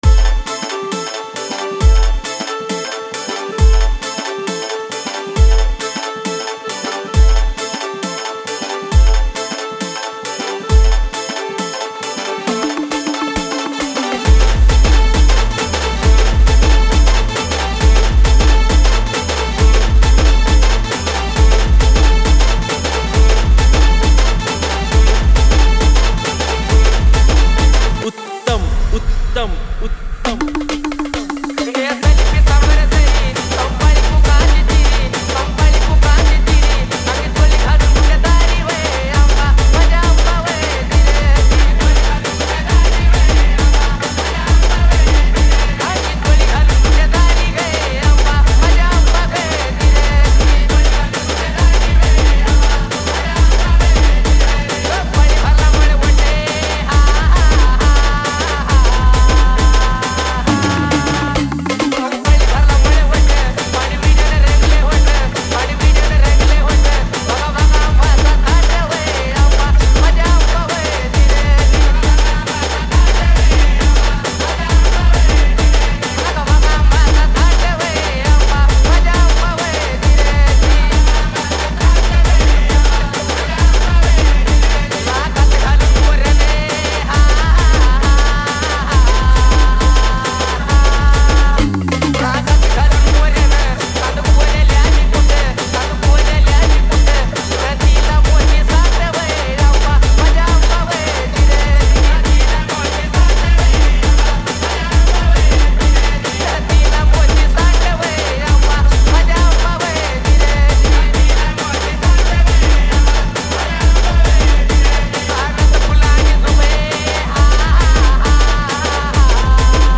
Category : Navratri Dj Remix Song